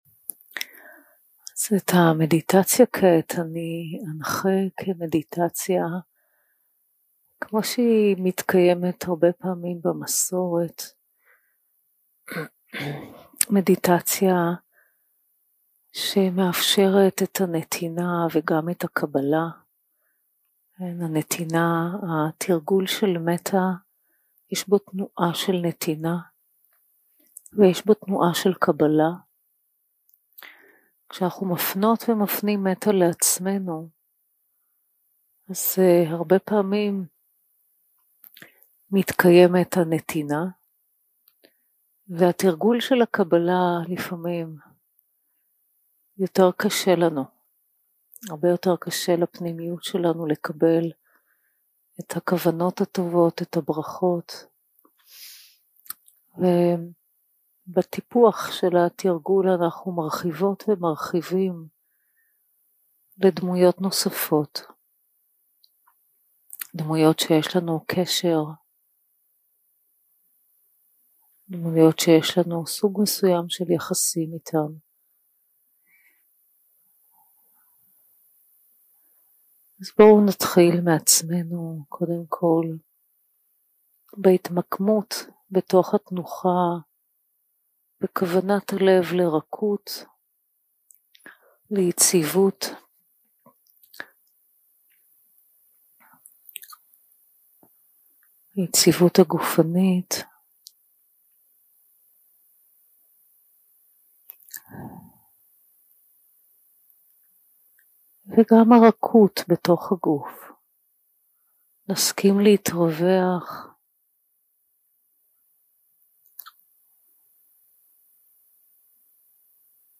יום 4 – הקלטה 11 – צהריים – מדיטציה מונחית – תנועה של נתינה וקבלה 0:00 0:00 סוג ההקלטה: Dharma type: Guided meditation שפת ההקלטה: Dharma talk language: Hebrew